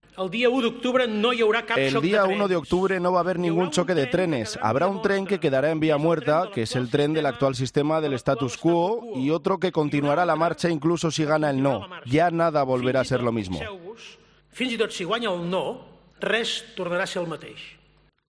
Puigdemont ha intervenido en el acto de presentación de las garantías del eventual referéndum del 1 de octubre desde el escenario del Teatre Nacional de Catalunya, rodeado del resto del Govern, diputados de JxSí, la presidenta del Parlament, Carme Forcadell, y unas urnas de metacrilato.